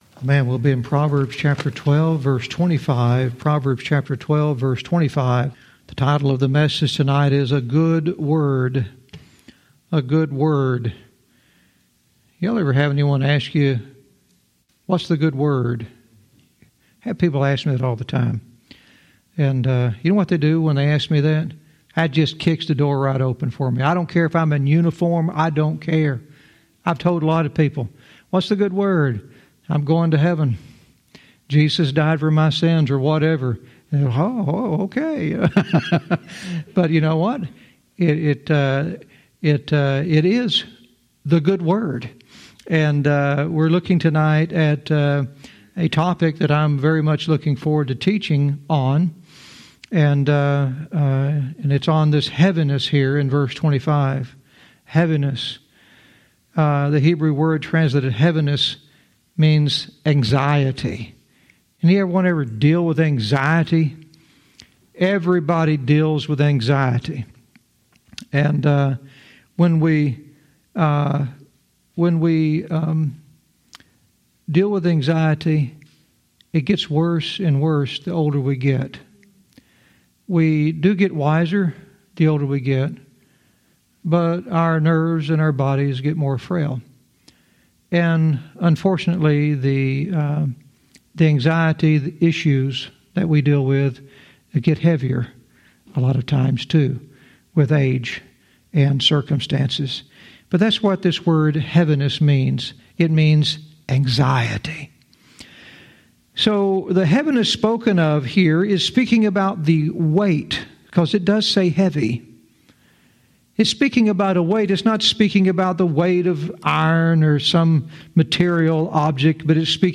Verse by verse teaching - Proverbs 12:25 "A Good Word"